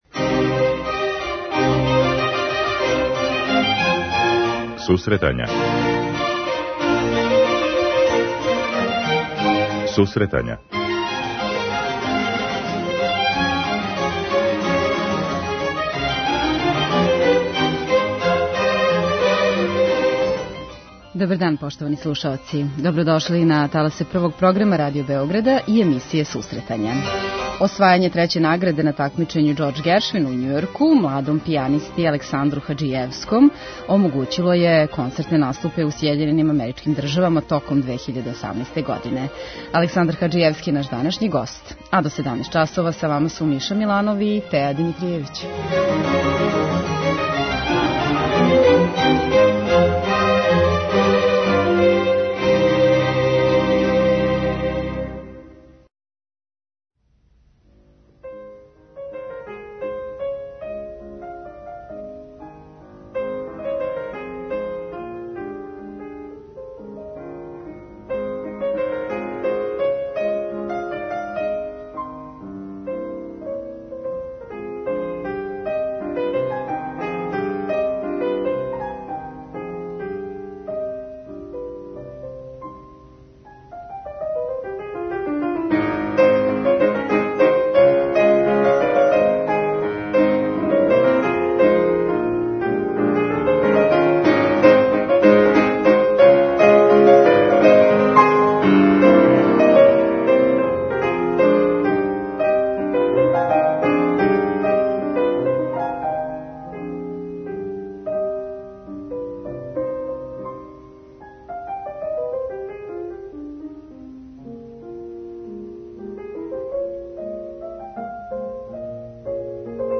Музичка редакција Емисија за оне који воле уметничку музику.